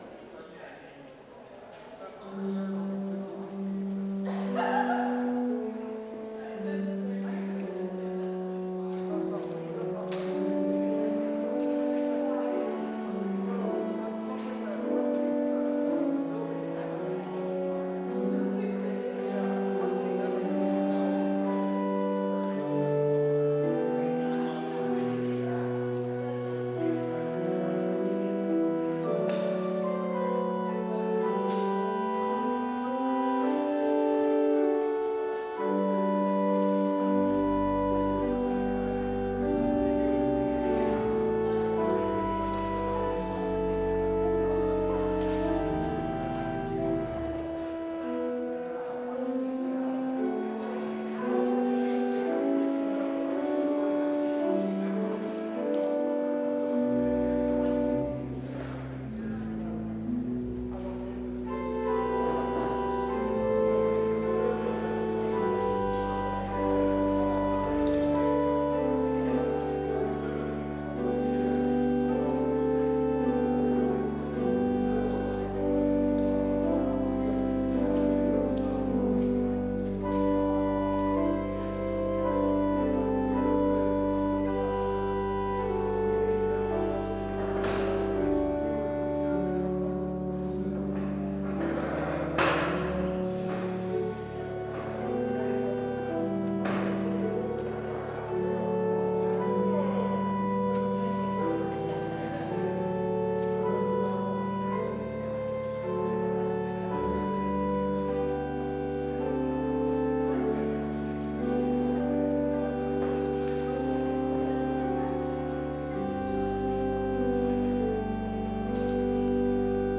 Complete service audio for Chapel - February 11, 2021